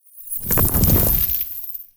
Free Frost Mage - SFX
frozen_wall_09.wav